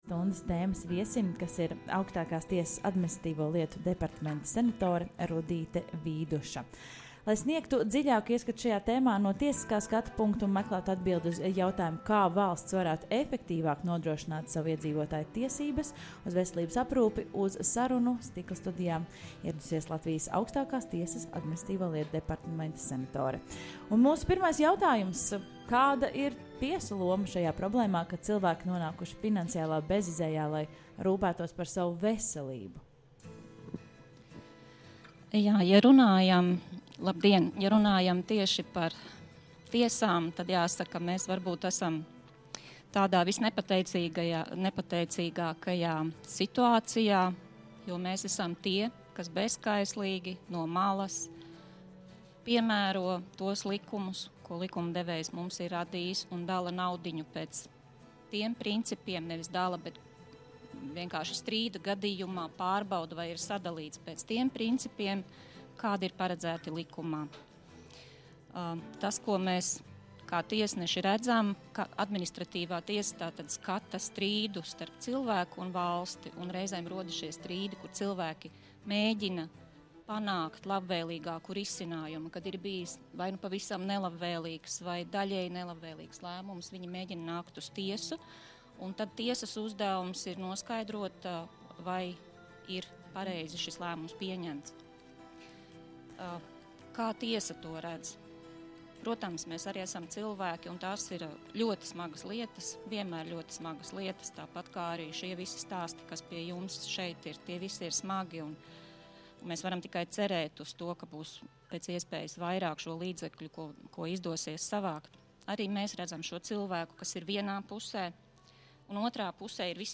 Labdarības maratona "Dod pieci!" stikla studijā viesojas Augstākās tiesas Administratīvo lietu departamenta senatore Rudīte Vīduša.